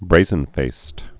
(brāzən-fāst)